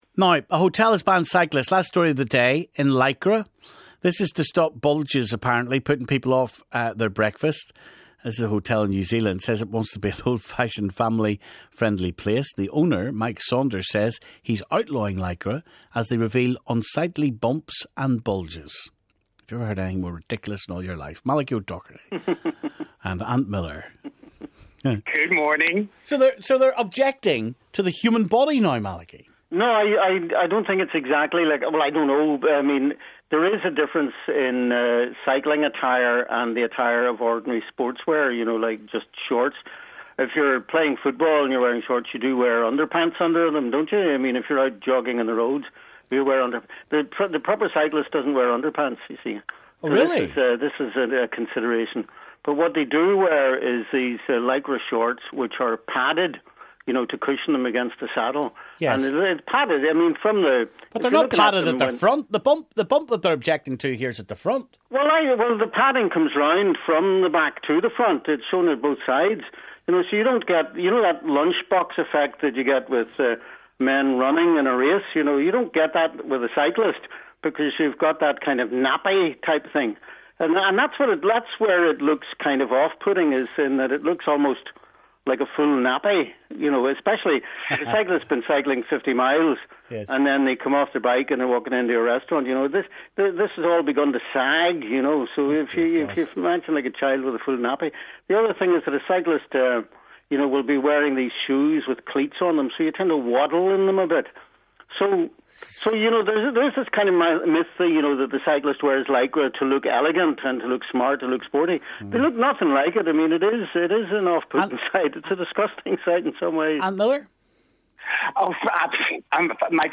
We got the views of commentators